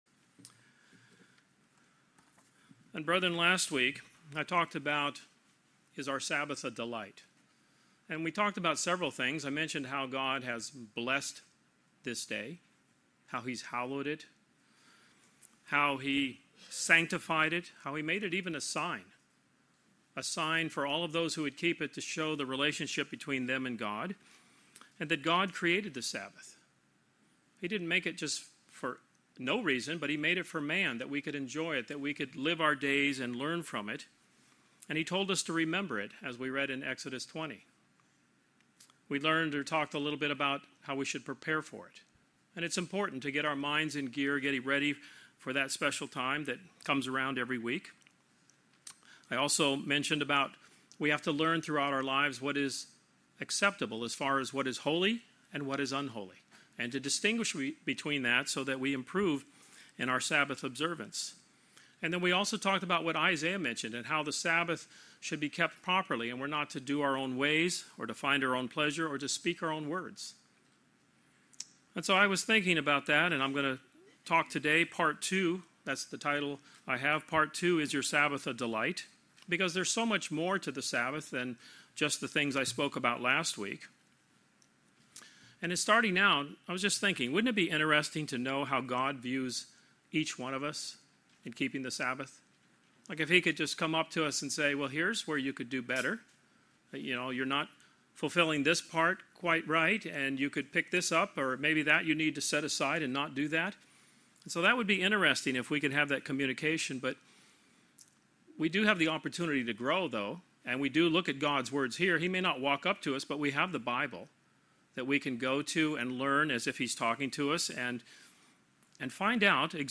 The weekly Sabbath plays a significant role in the lives of Christians. This sermon covers three additional points which are helpful in fulfilling the command to delight in the Sabbath.